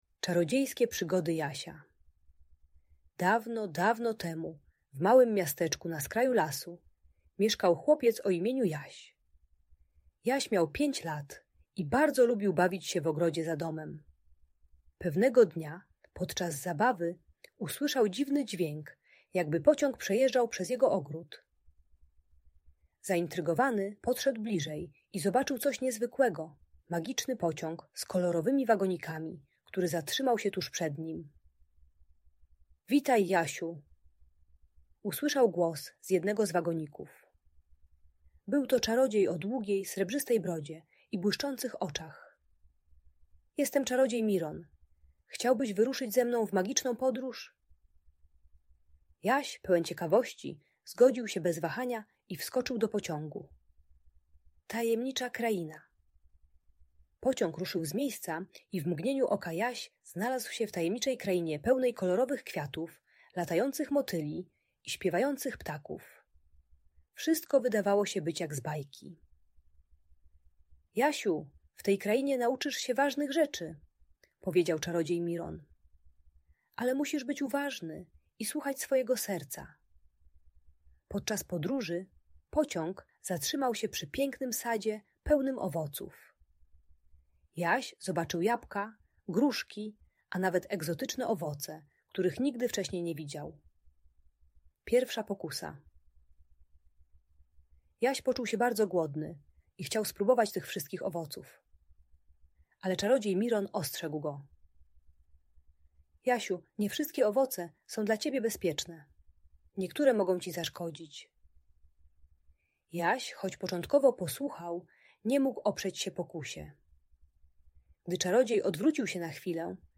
Czarodziejskie Przygody Jasia - Audiobajka